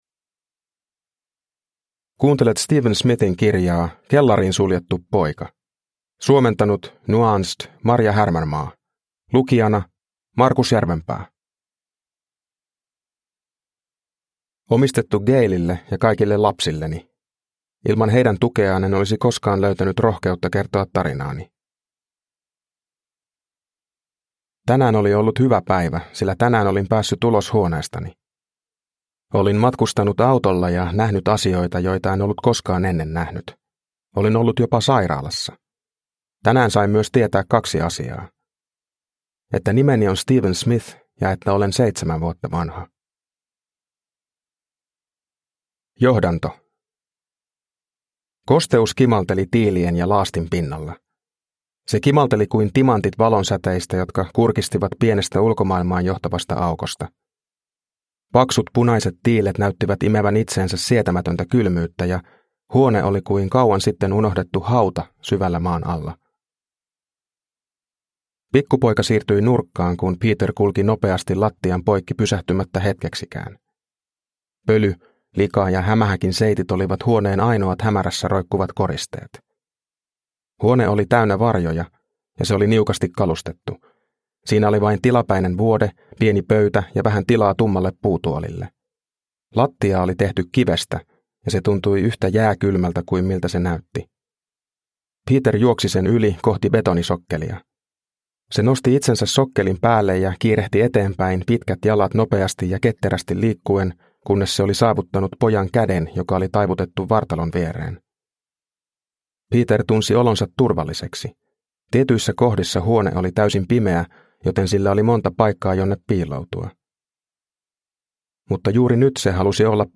Kellariin suljettu poika – Ljudbok – Laddas ner